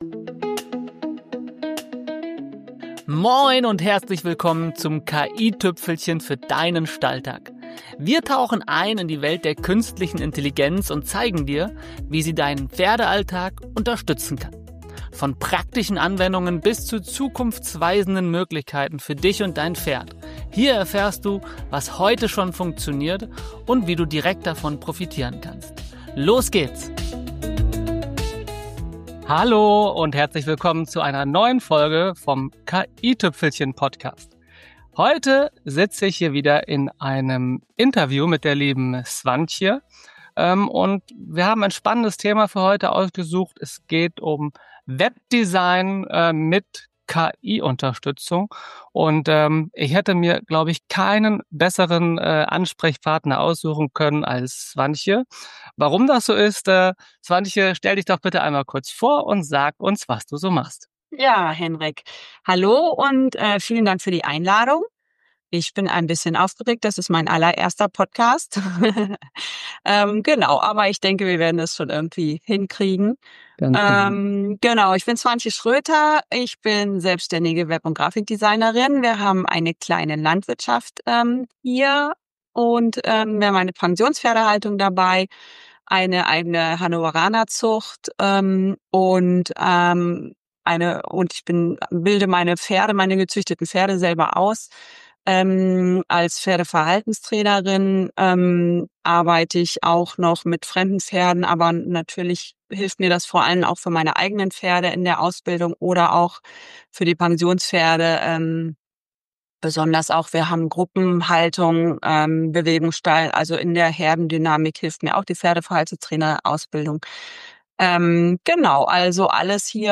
#12 - Webdesign mit KI - Interview